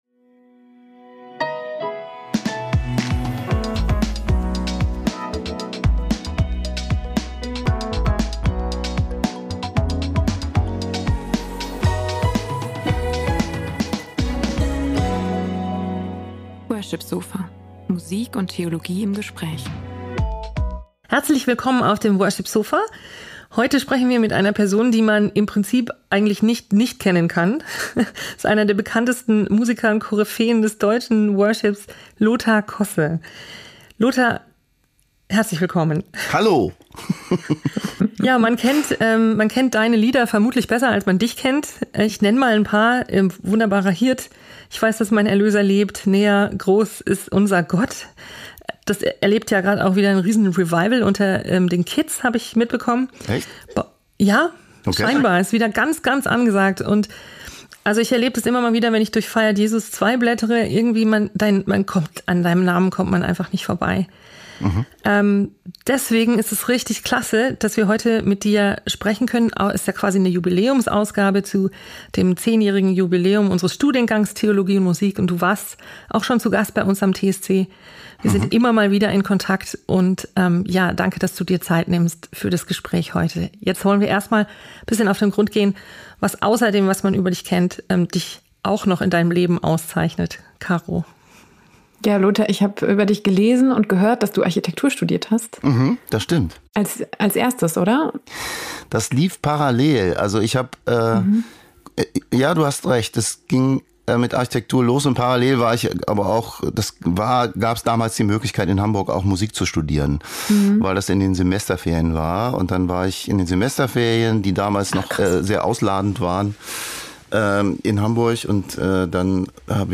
Ein Gespräch über Leidenschaft, Inspiration – und den Klang, den jeder Mensch in sich trägt.